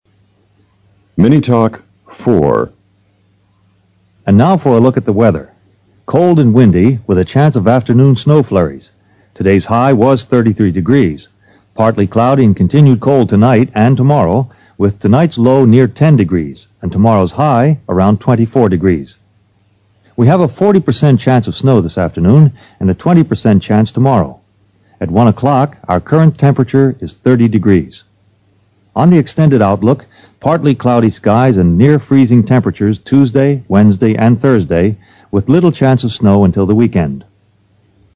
SECTION 1: LISTENING COMPREHENSION
PART C: MINI TALKS